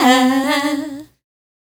Vox Lix 139-D.wav